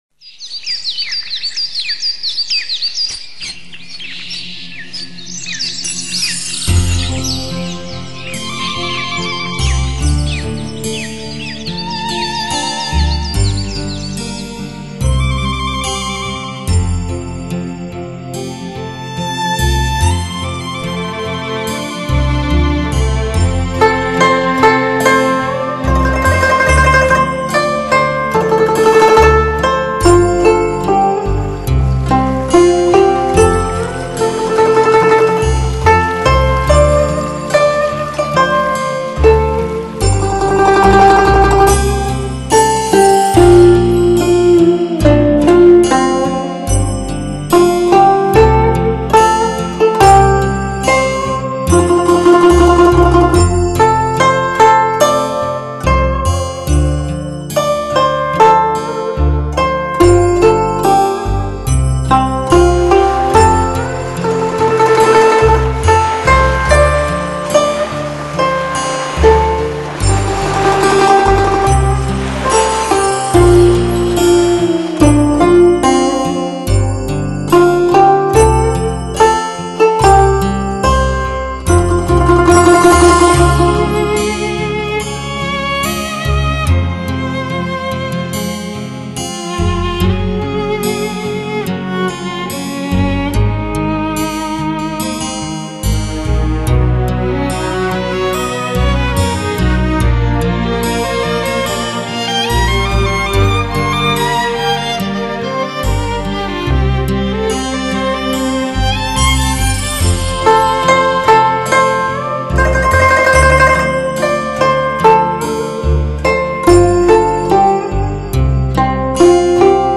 古筝